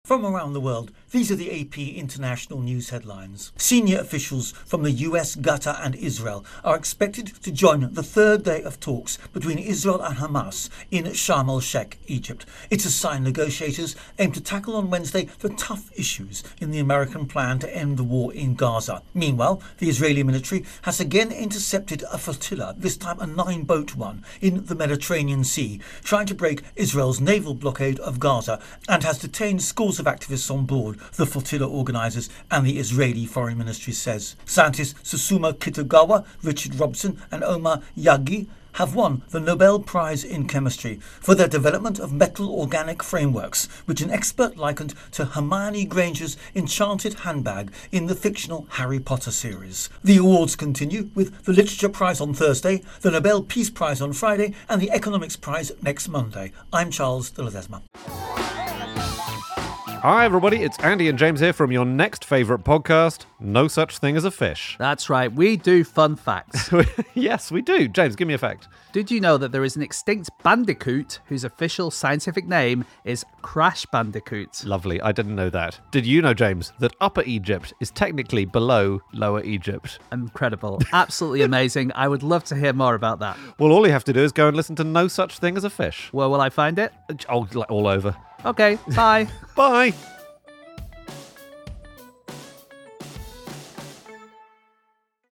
The latest news headlines